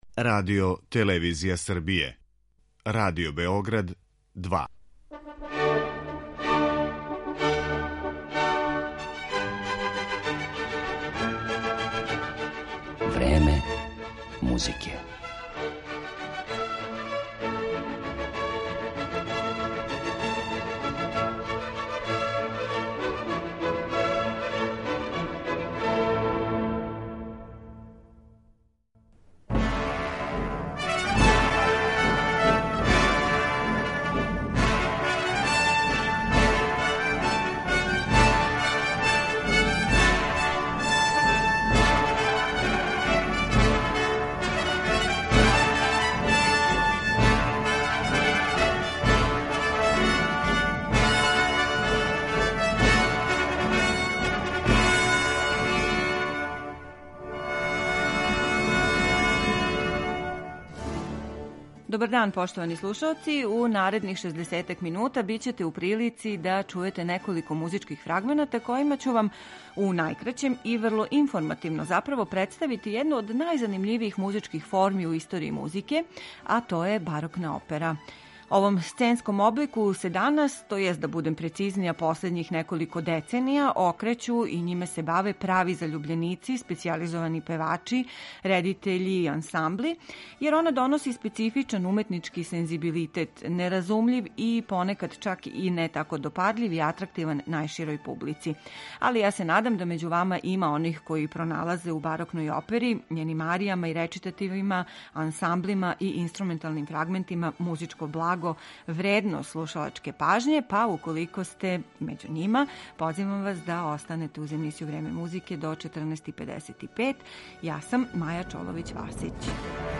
Имаћете прилику да чујете фрагменте из неких од најпознатијих сценских дела, чији су аутори, између осталих, Монтеверди, Персл, Лили, Кавали и Хендл.